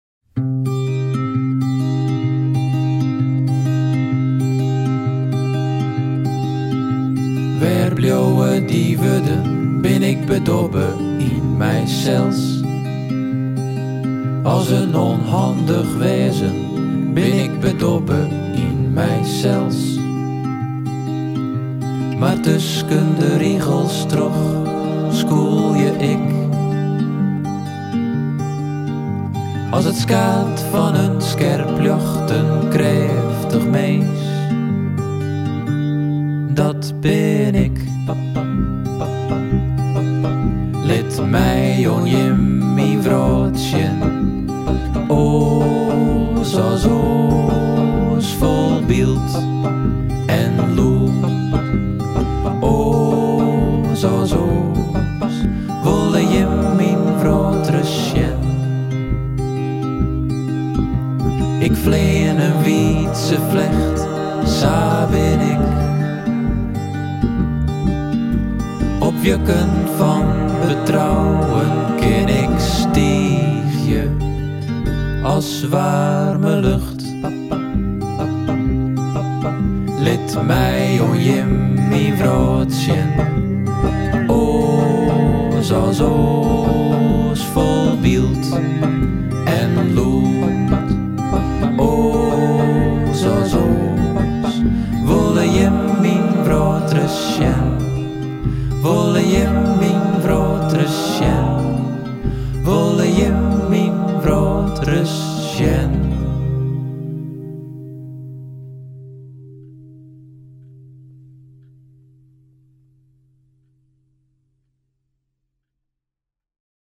Zanger | Muzikant | Songwriter